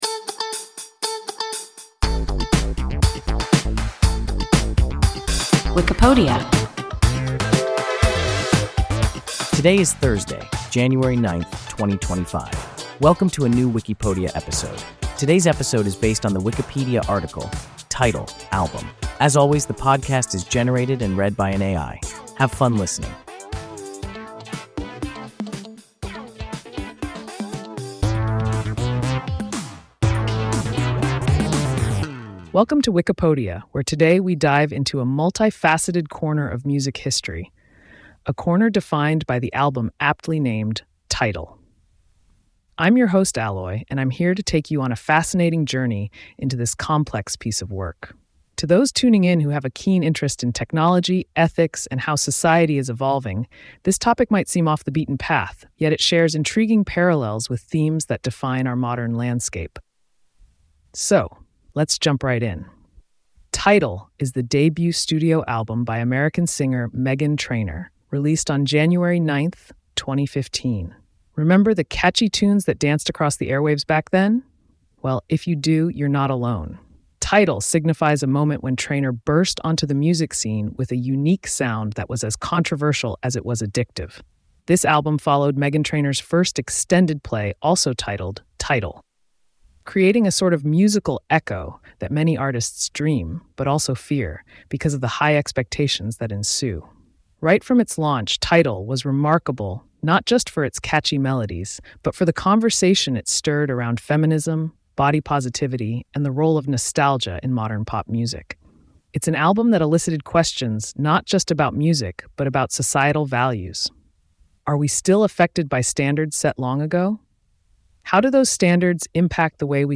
Wikipodia – an AI podcast